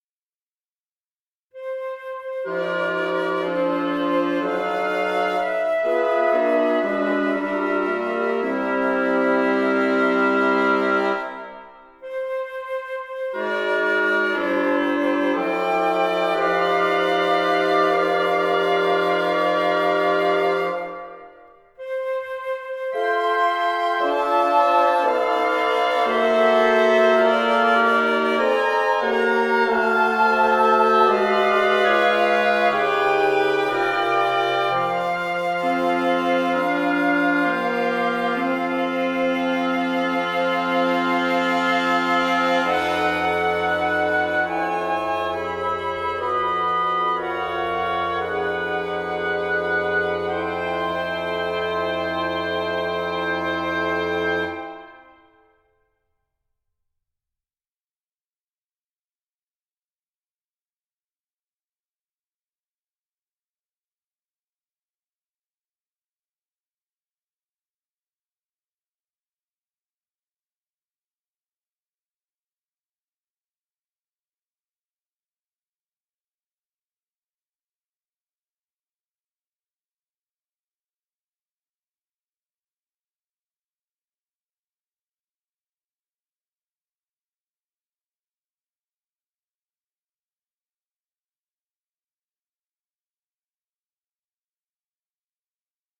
Especially the crescendos.
It is loosely centered around porcupine's MOS's and MODMOS's in 22-TET.